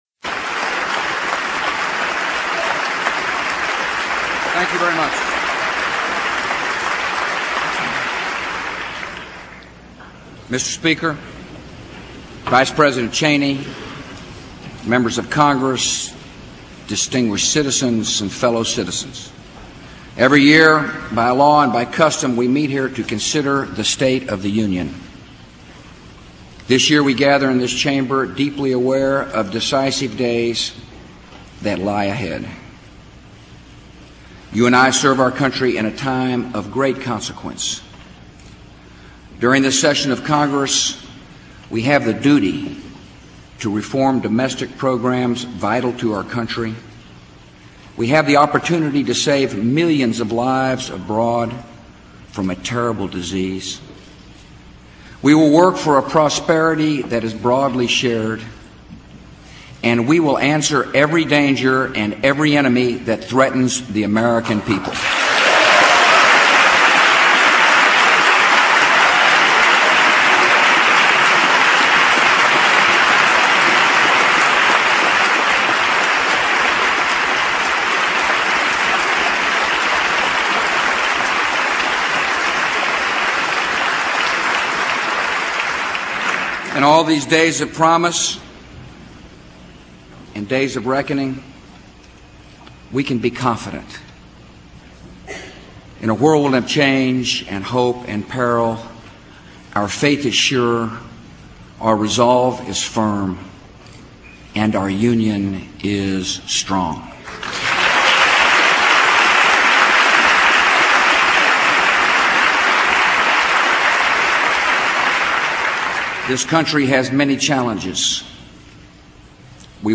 Tags: George W. Bush State of the Union George W. Bush State of the Union George W. Bush speech President